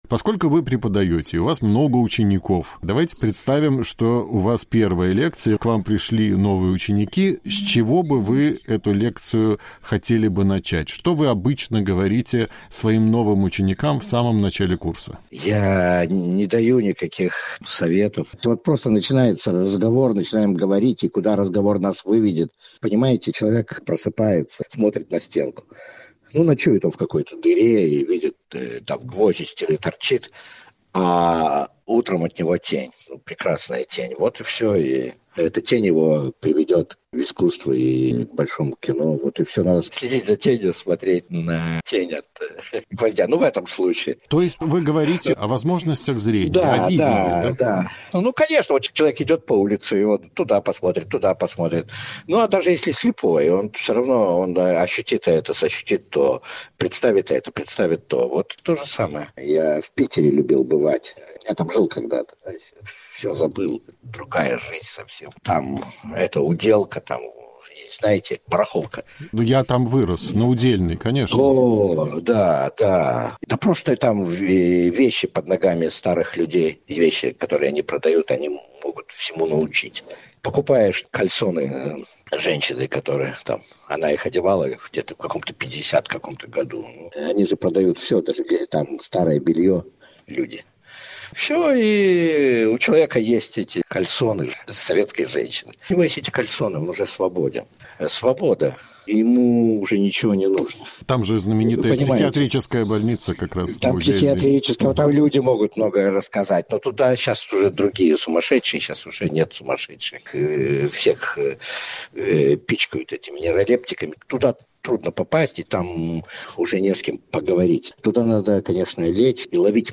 Кинорежиссера Артура Аристакисяна мой звонок застал в Париже, где он с недавних пор живет в спартанских условиях. Но жалоб я не услышал, потому что бедность, в том числе и крайние ее формы, – одна из главных тем кинематографа Аристакисяна.